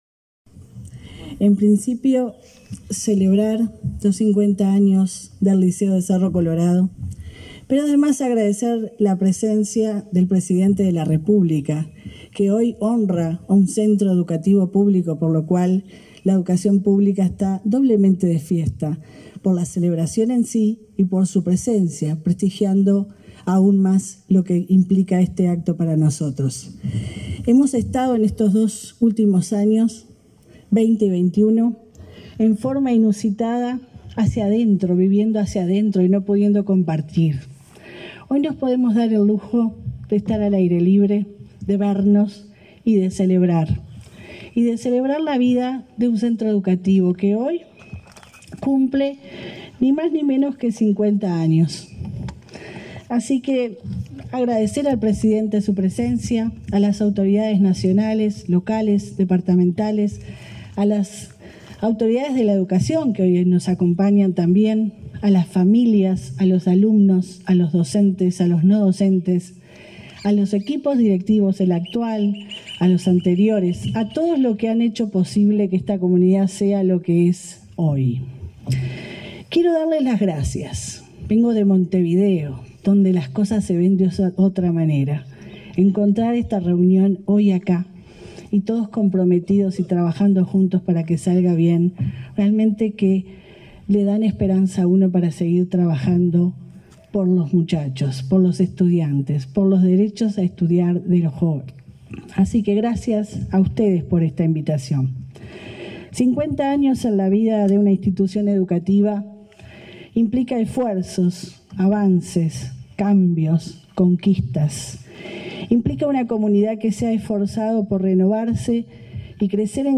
Palabras de la directora de Secundaria, Jenifer Cherro
La directora de Secundaria, Jenifer Cherro, participó este sábado 20 en Florida en el festejo por el cincuentenario del liceo de Cerro Colorado.